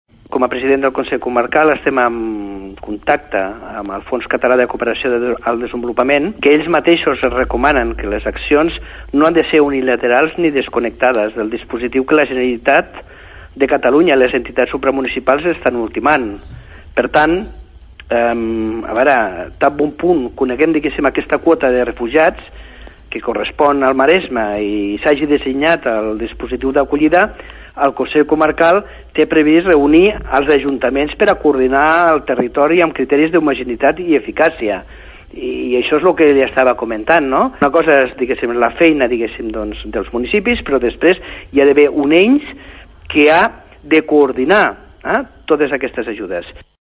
A hores d’ara, el consell comarcal del Maresme té previst reunir els consistoris de la comarca per coordinar el territori amb criteris d’homogeneïtat i eficàcia en el moment que es conegui la quota de refugiats que correspondrà a la comarca i s’hagi dissenyat el dispositiu d’acollida. Així ho detalla el president del consell comarcal del Maresme, Miquel Àngel Martínez.